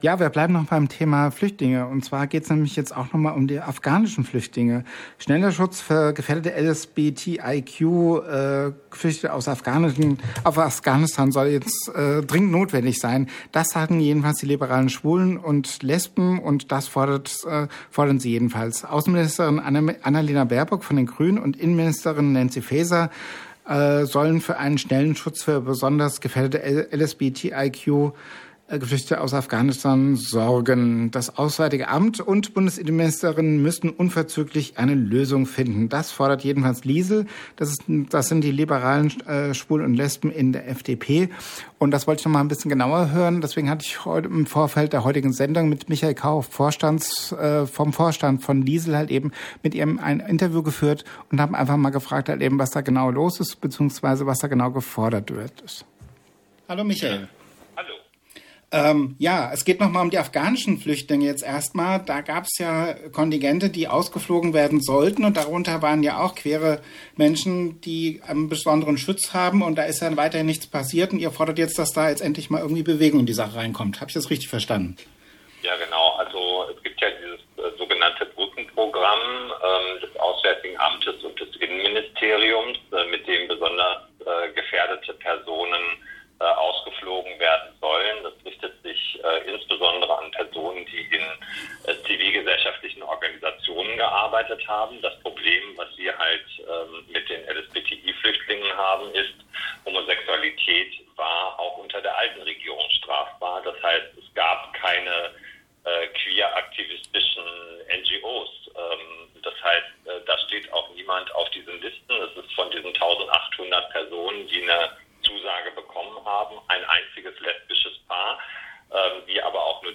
LiSL von Michael Kauch spricht mit uns über die Forderung